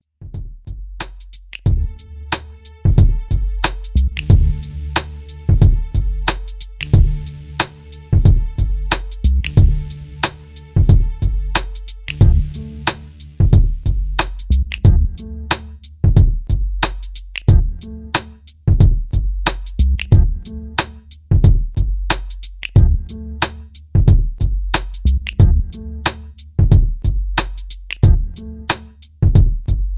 Grimey East Coast HipHop